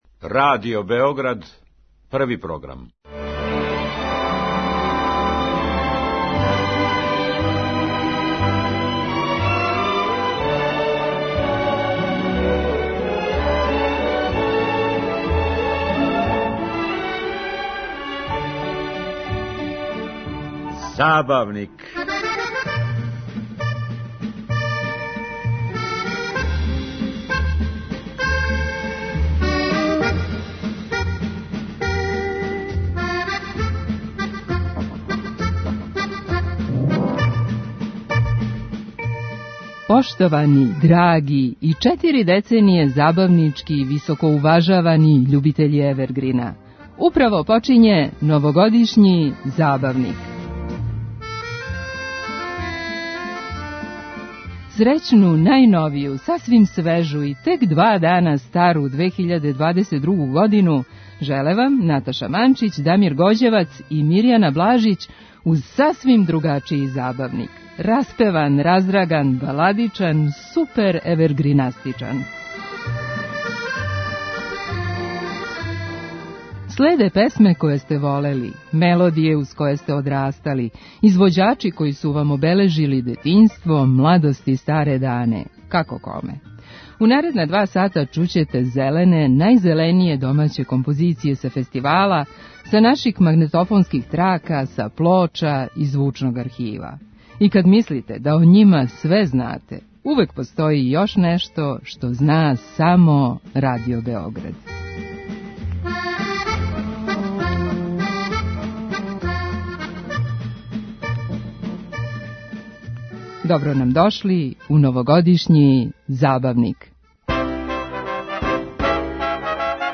Распеван, раздраган, баладичан, суперевергринастичан!
Чућете зелене, најзеленије домаће композиције са фестивала, са наших магнетофонских трака, са плоча, из Звучног архива...